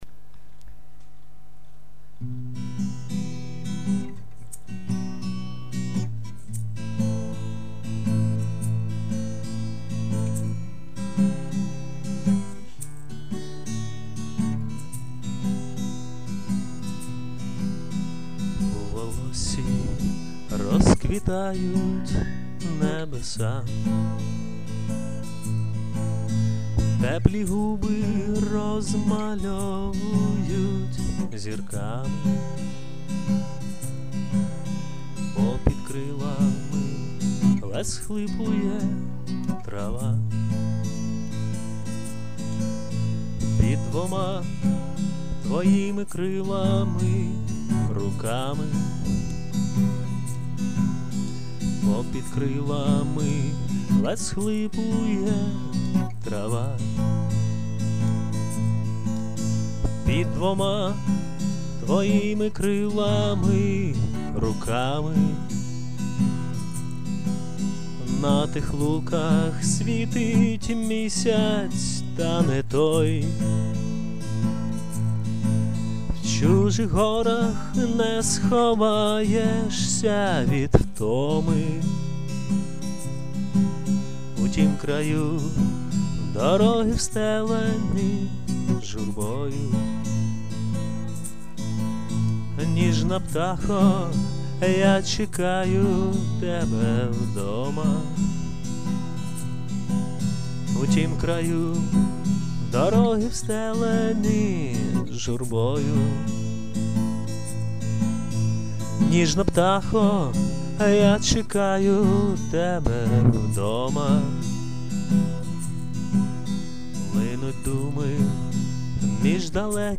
Прошу пробачити за якість виконання - записав загалом менше, ніж за годину, в складних умовах: діти намагалися допомогти.